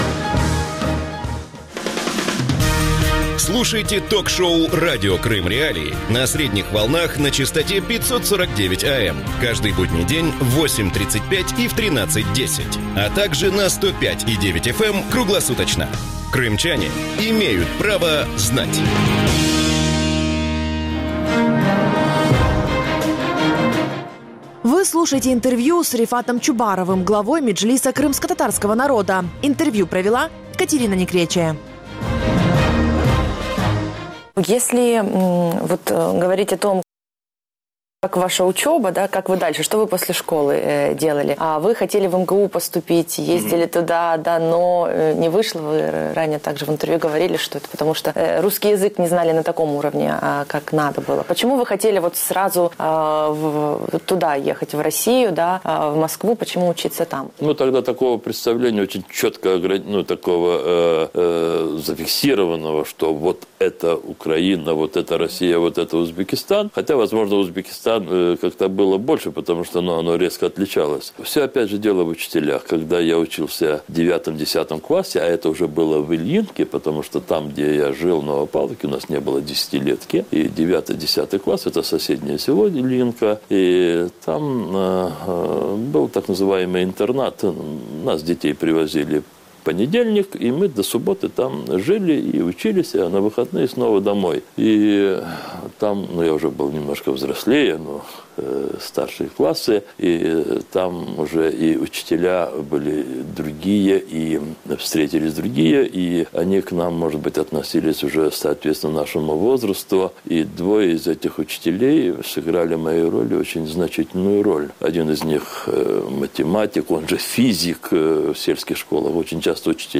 Интервью с Рефатом Чубаровым. Часть 2